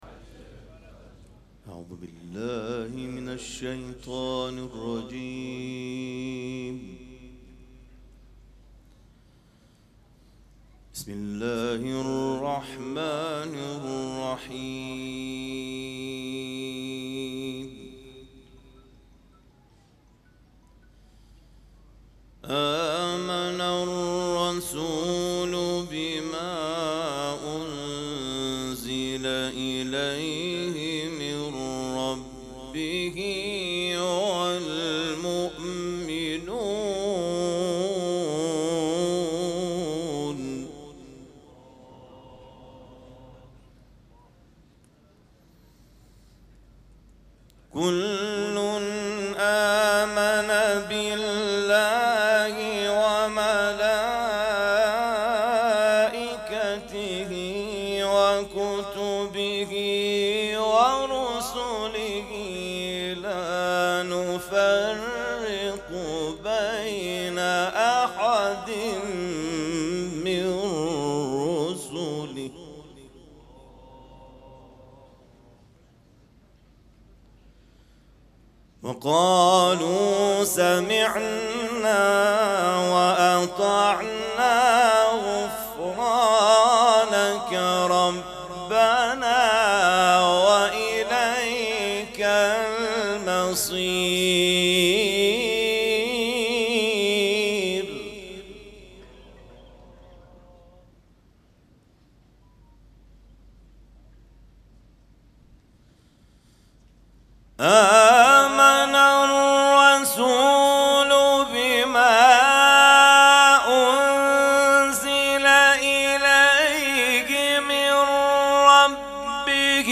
مراسم عزاداری شب سوم محرم الحرام ۱۴۴۷
قرائت قرآن